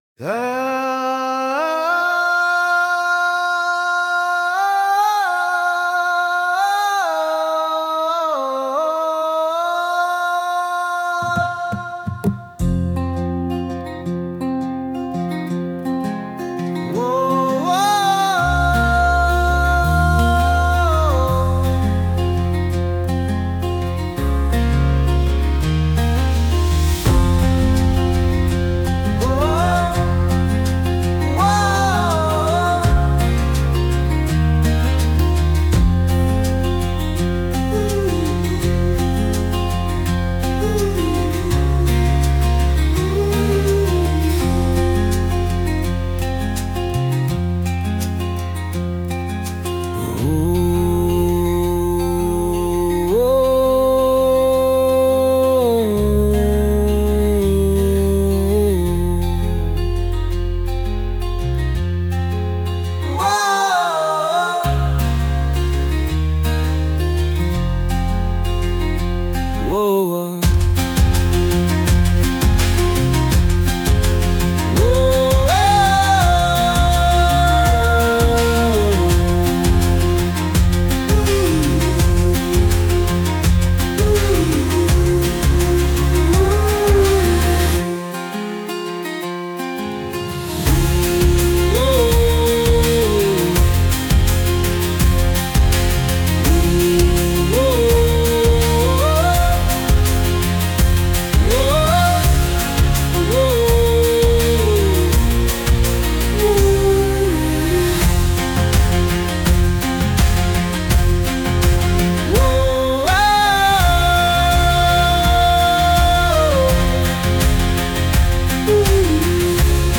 With Vocals / 歌あり
男性の「ウォーオーオー」という、魂の叫びのような低い掛け声が特徴的な、エキゾチックな民族音楽調の楽曲です。
ゆったりとした重厚なリズムで進むため、一つ一つの動きを大きく、粘り強く見せる演技に最適。